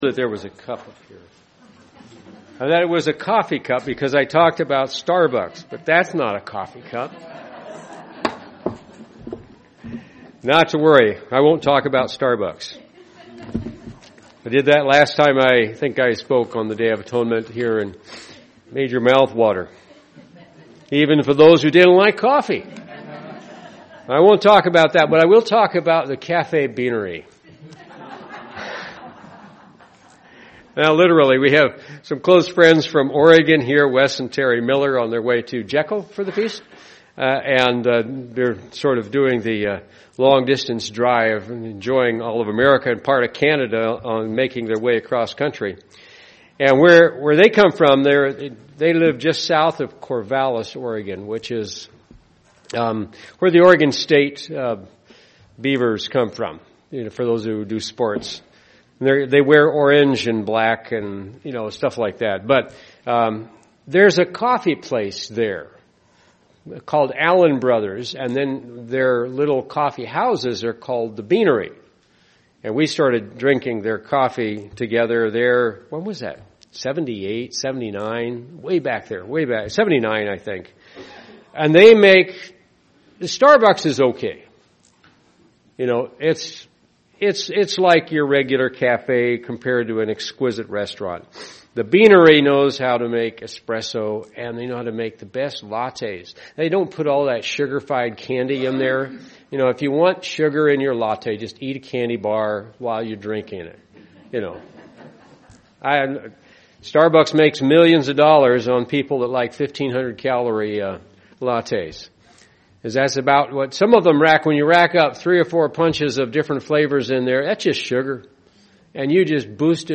The Day of Atonement helps us to focus on and overcome our enemy's tactics to join in the victory of God's Kingdom. This message was given on the Day of Atonement.
UCG Sermon Studying the bible?